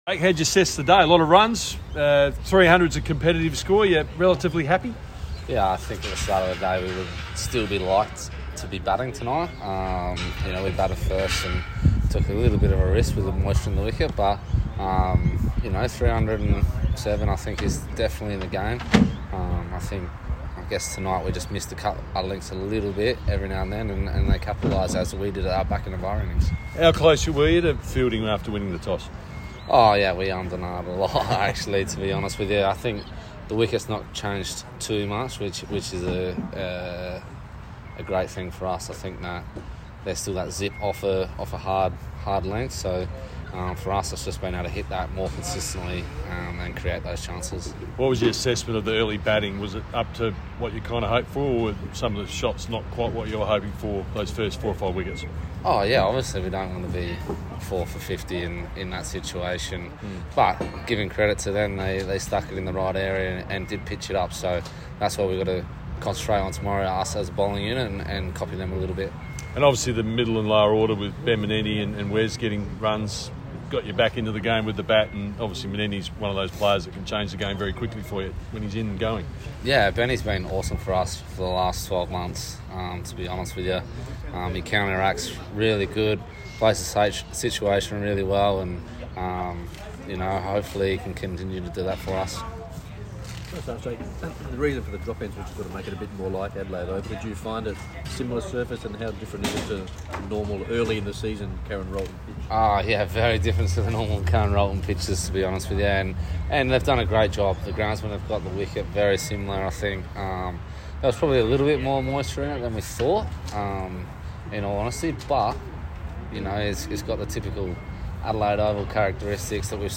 South Australian Captain Jake Lehmann speaks after Day 1